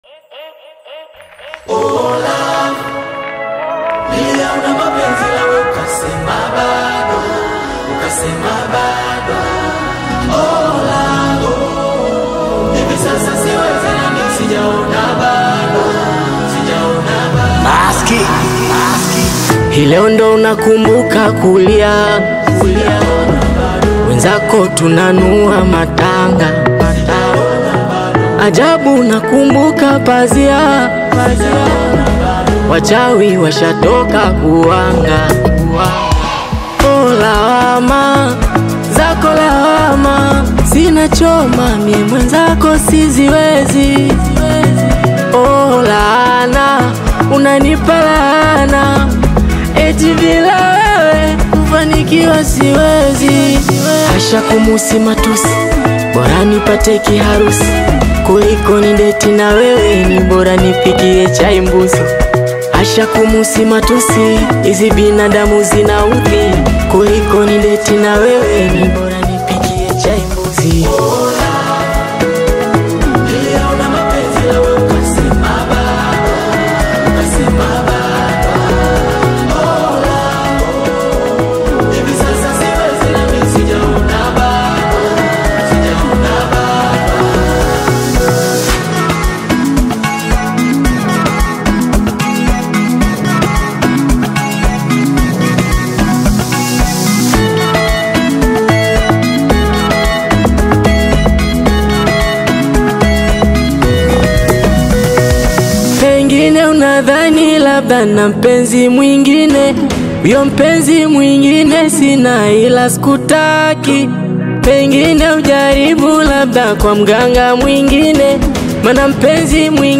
modern Bongo Flava sound